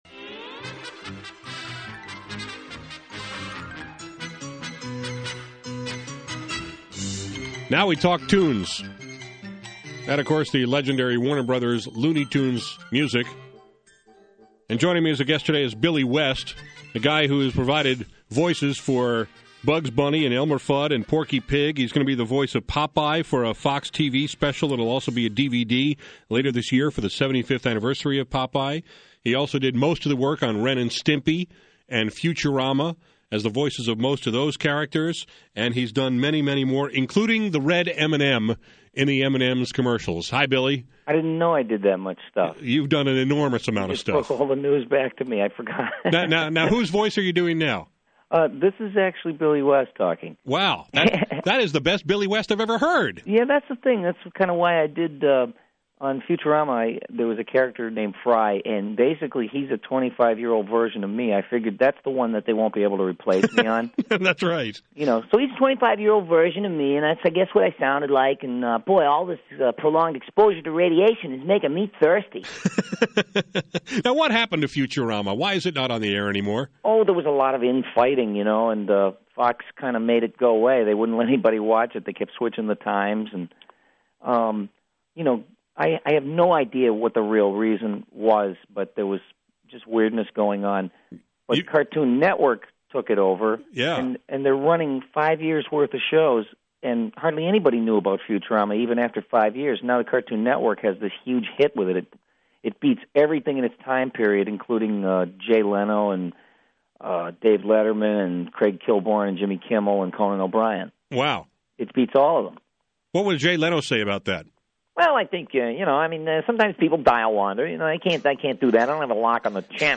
Here’s my conversation with Billy West, one of the most gifted voice men in show business explained how he does the voice of Popeye, why he dislikes some celebrities doing animated voices, and how he feels about people who complain about his work.